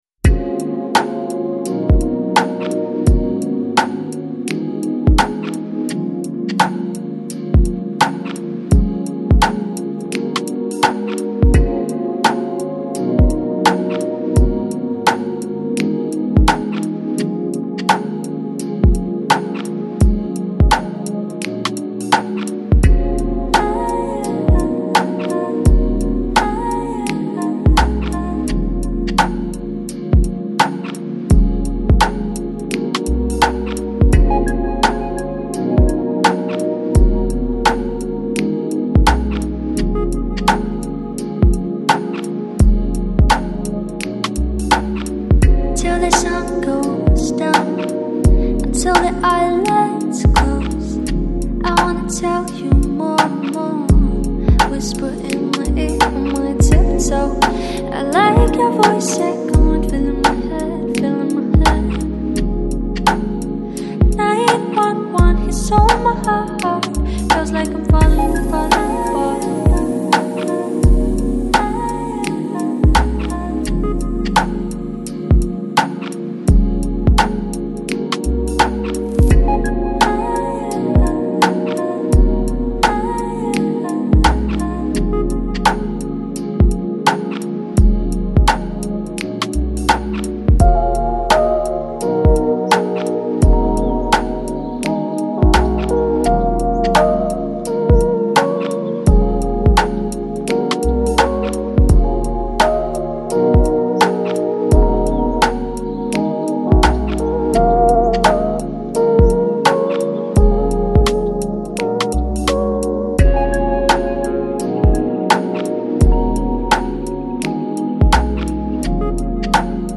Жанр: Downtempo, Chillout